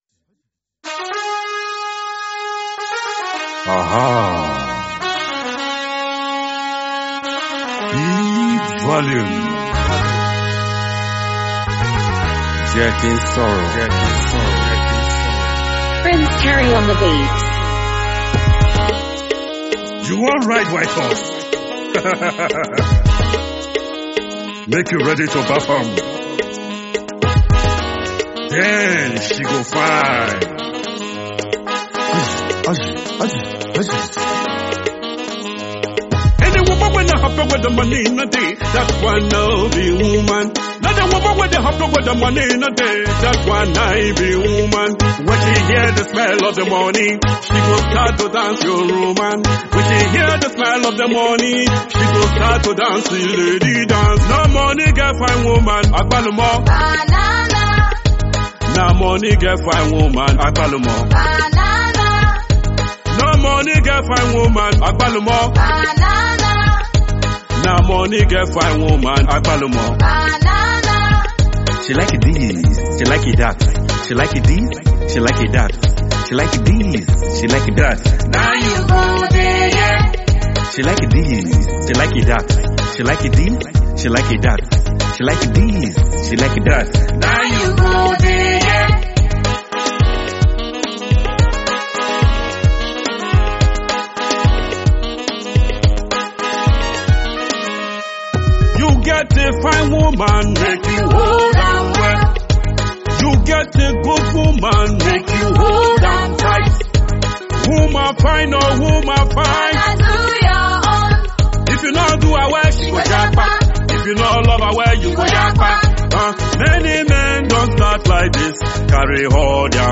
Afrobeat song